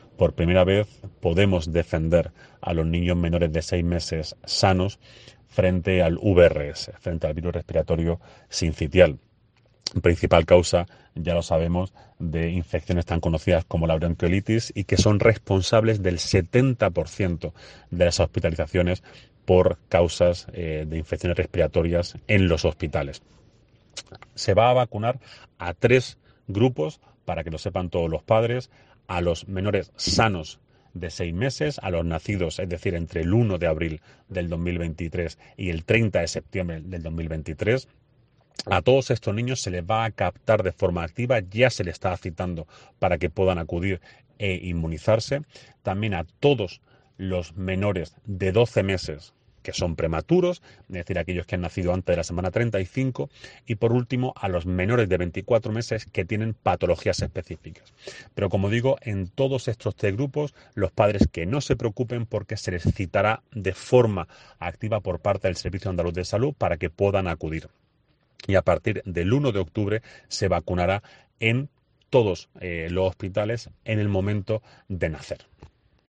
Director general de Salud Pública y Ordenación Farmacéutica, Jorge del Diego